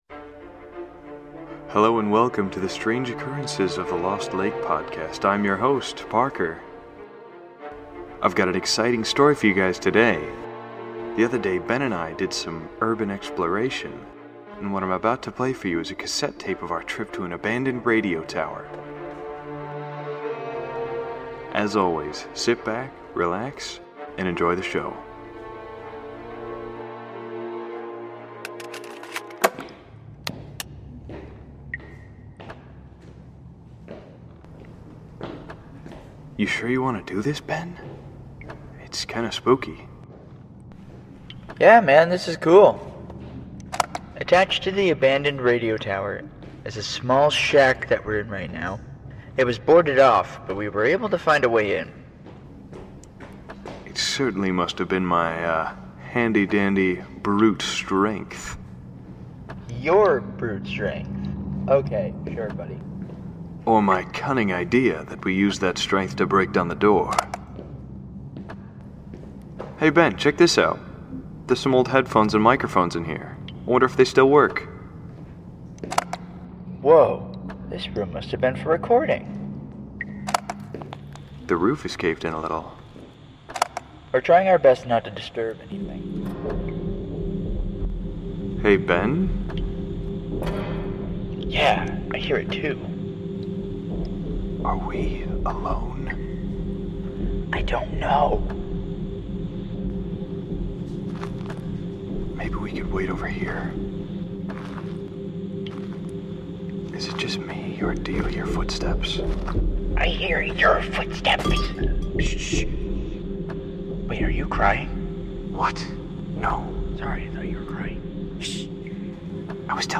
Format: Audio Drama
Voices: Full cast